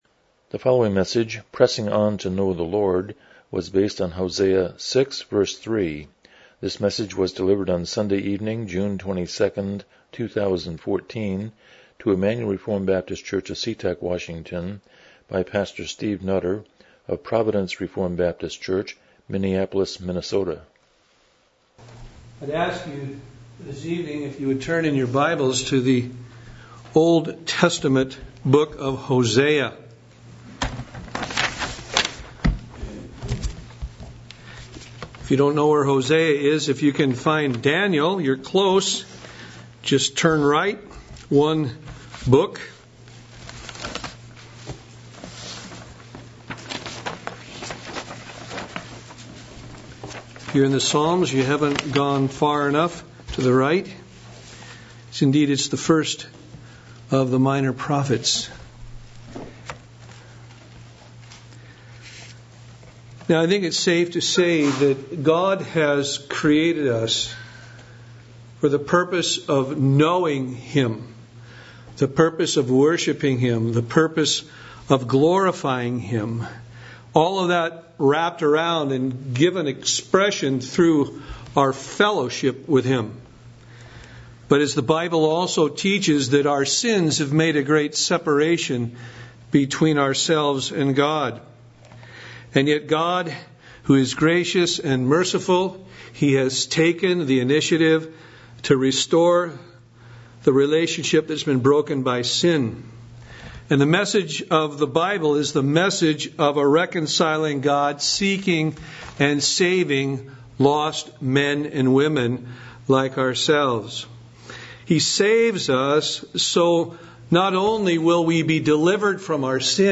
Passage: Hosea 6:3 Service Type: Evening Worship